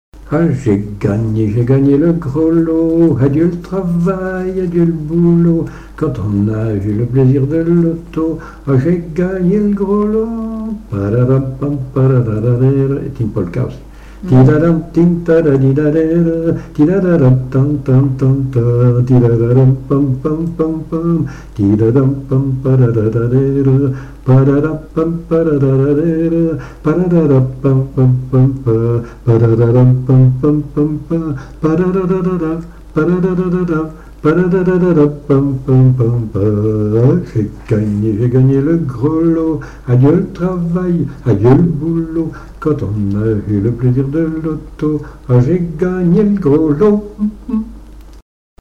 Rochetrejoux
Polka
Chants brefs - A danser
Pièce musicale inédite